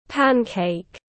Bánh kếp tiếng anh gọi là pancake, phiên âm tiếng anh đọc là /ˈpæn.keɪk/
Pancake /ˈpæn.keɪk/